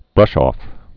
(brŭshôf, -ŏf)